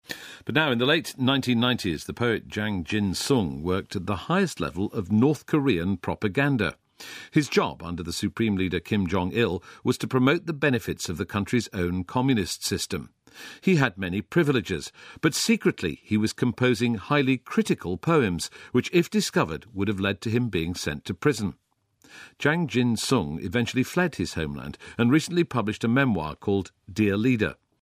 【英音模仿秀】最美味的东西 听力文件下载—在线英语听力室